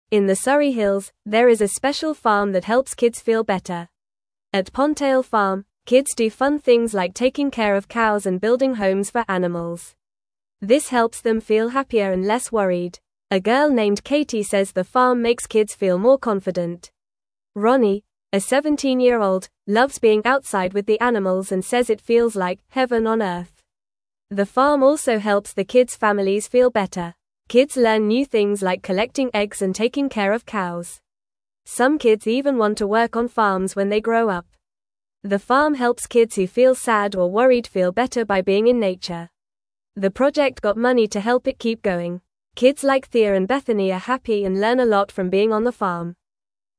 Fast
English-Newsroom-Beginner-FAST-Reading-Happy-Kids-at-Pondtail-Farm-with-Animals.mp3